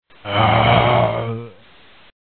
mgroan3.mp3